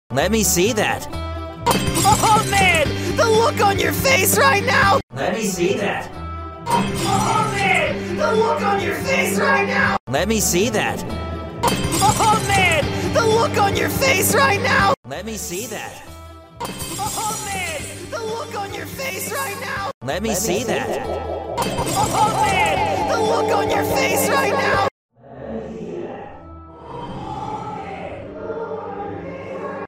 6 THE CUPHEAD SHOW ANIMATION! sound effects free download